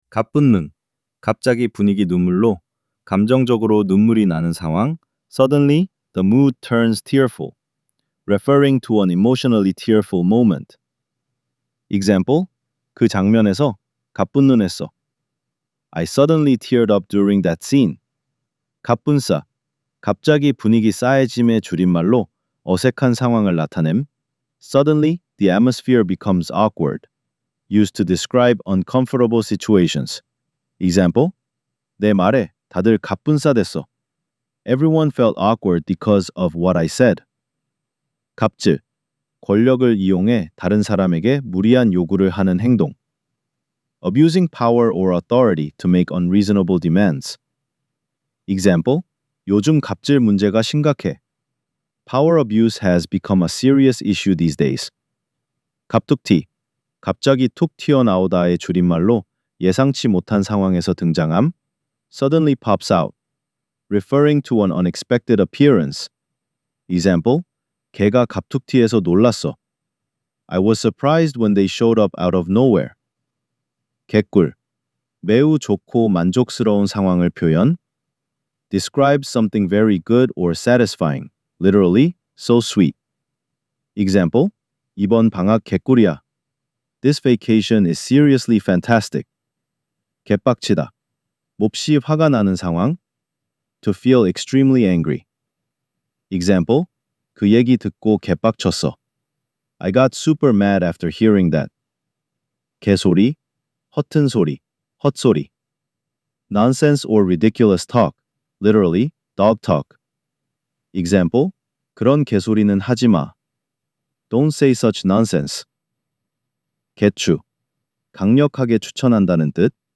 2) Korean Slang Decoded: Vol. 02 – Audio Narration